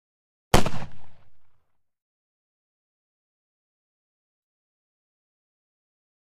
Flintlock Pistol: Single Fire; Single Shot Of Flintlock Pistol. Sharp, Loud Crack At Medium Close Perspective. Gunshots.